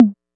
808 TOM 02.wav